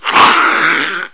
assets/psp/nzportable/nzp/sounds/zombie/d0.wav at 145f4da59132e10dabb747fa6c2e3042c62b68ff